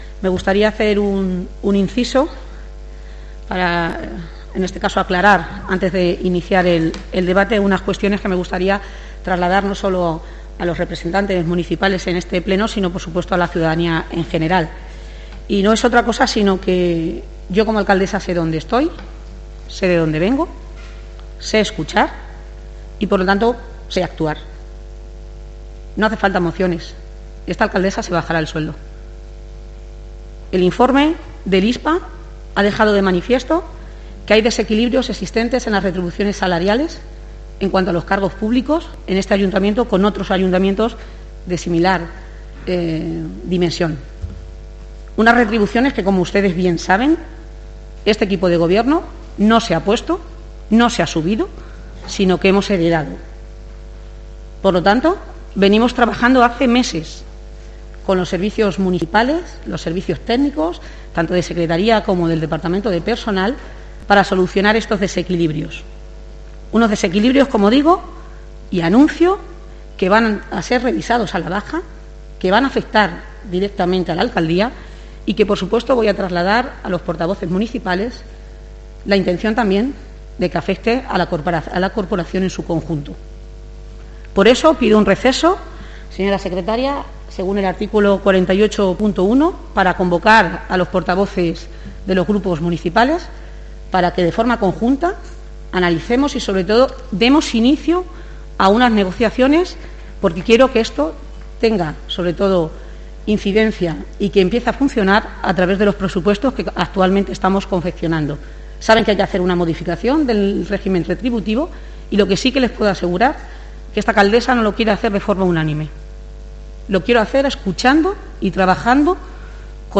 García Élez ha tomado la palabra antes del debate de la moción para trasladar su intención de revisar los sueldos de todos los ediles de la corporación pero hacerlo pro unanimidad de todos los grupos políticos municipales y la concejala no adscrita.
Transcribimos las palabras de la alcaldesa que puedes escuchar pinchando en la foto de portada.